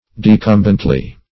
decumbently - definition of decumbently - synonyms, pronunciation, spelling from Free Dictionary Search Result for " decumbently" : The Collaborative International Dictionary of English v.0.48: Decumbently \De*cum"bent*ly\, adv. In a decumbent posture.